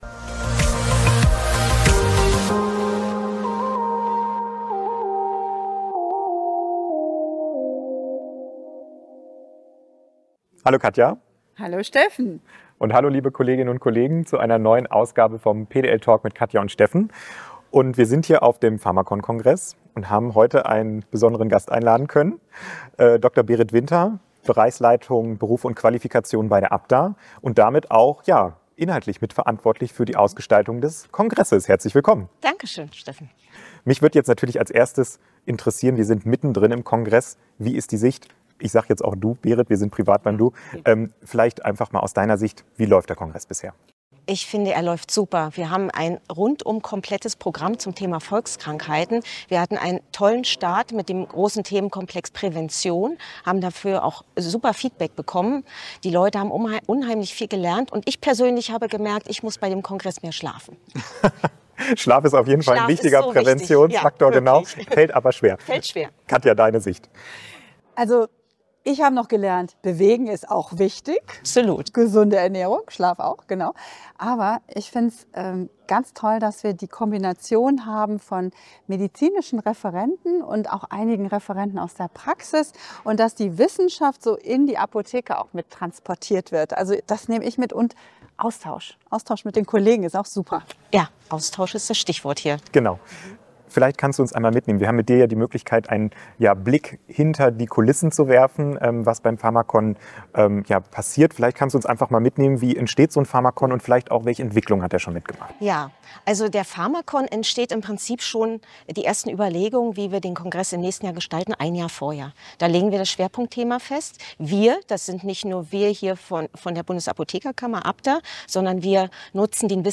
Live vom pharmacon